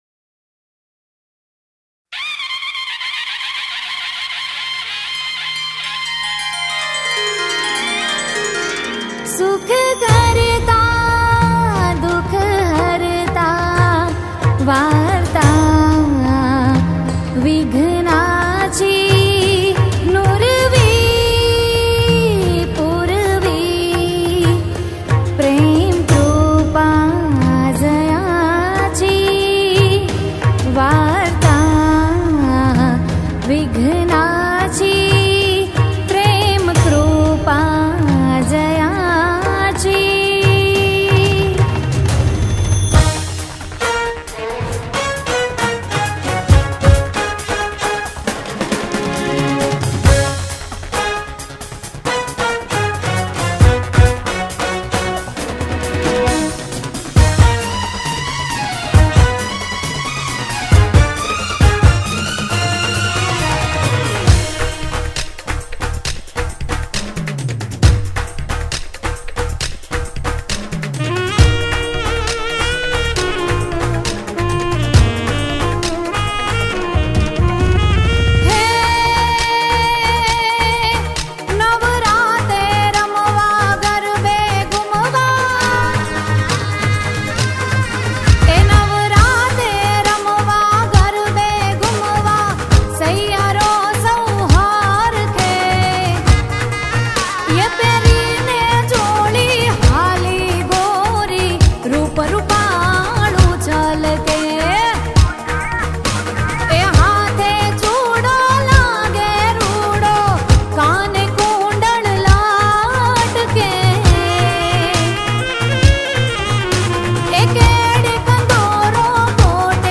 Navratri Garba Albums